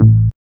1908R BASS.wav